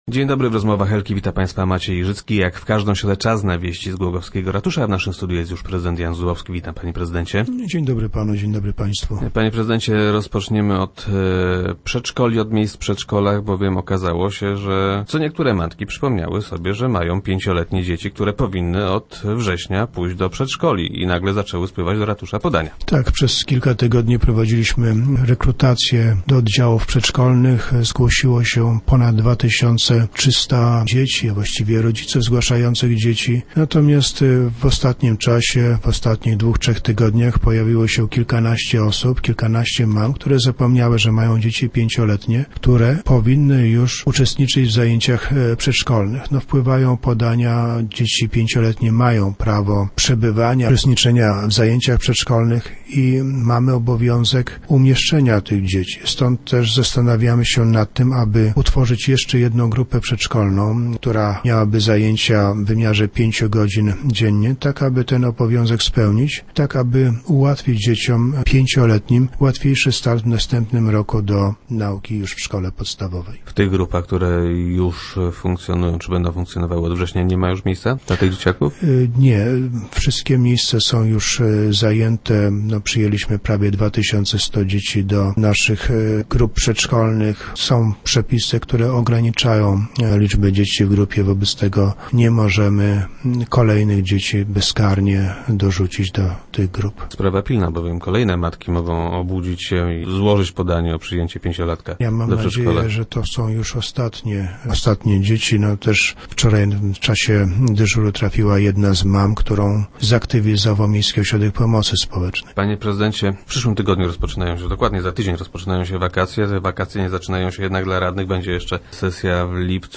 - Do ratusza trafiają podania rodziców pięciolatków, którzy zapomnieli, że ich pociechy powinny iść do przedszkola - informuje prezydent Jan Zubowski, który był gościem Rozmów Elki.